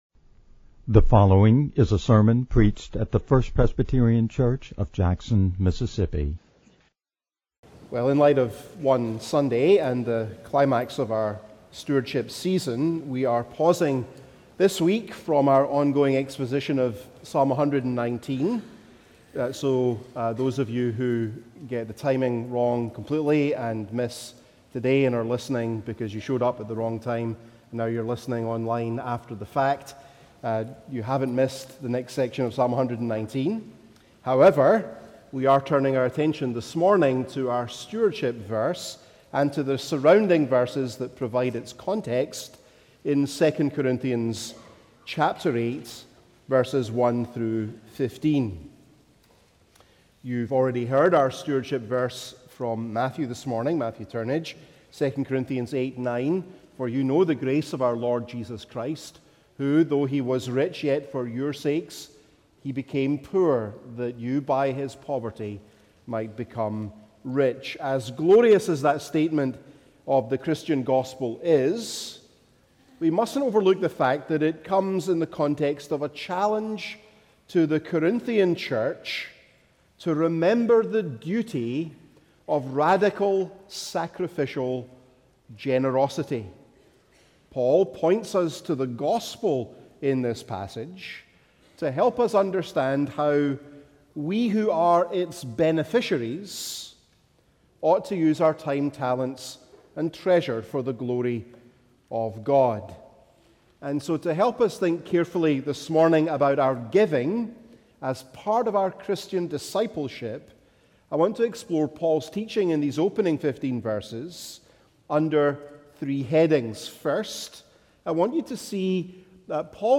Poverty-and-Riches-One-Sunday-Sermon.mp3